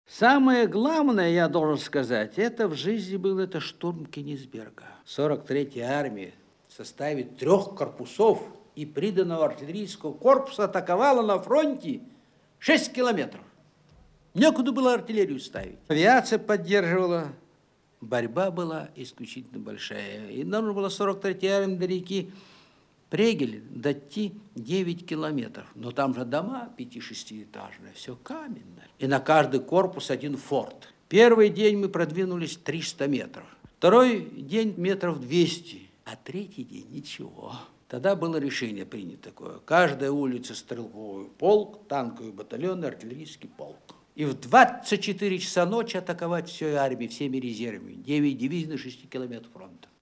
Командующий 43-ей армией генерал армии Афанасий Белобородов рассказывает о боях за Кёнигсберг (Архивная запись).